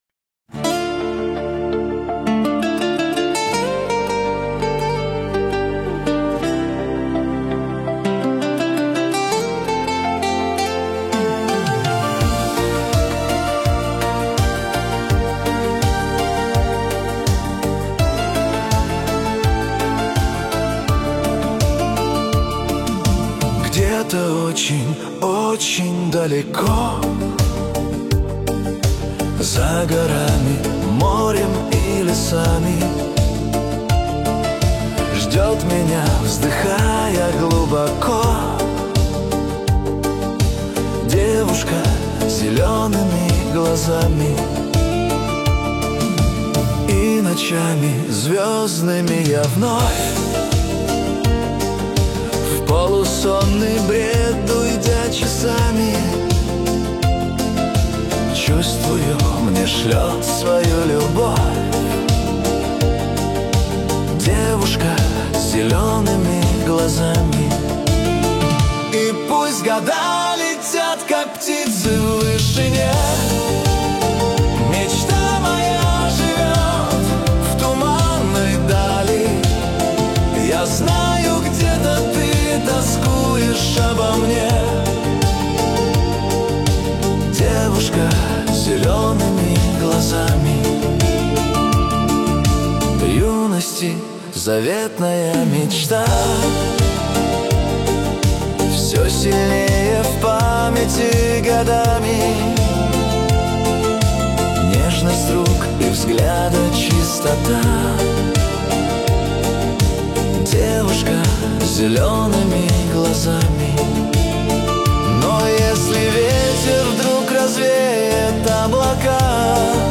Трогательная песня о любви